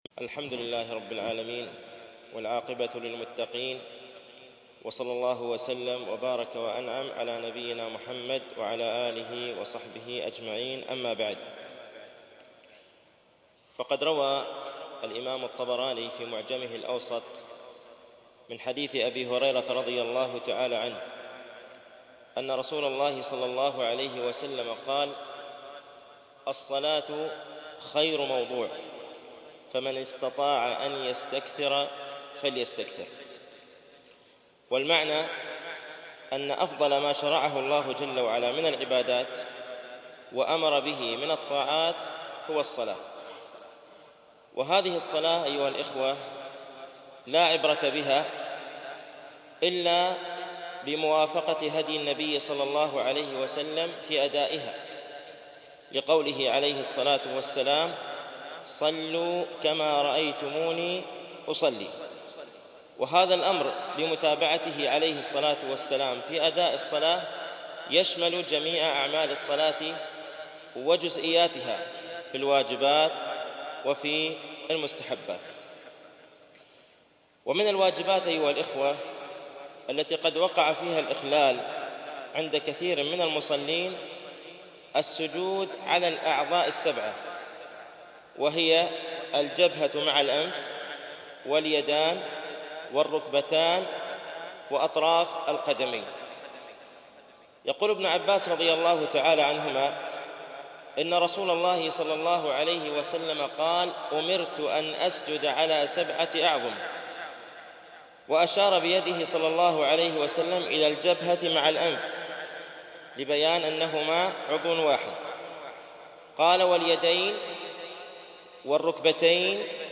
) الألبوم: شبكة بينونة للعلوم الشرعية المدة: 6:46 دقائق (3.12 م.بايت) التنسيق: MP3 Mono 22kHz 64Kbps (CBR)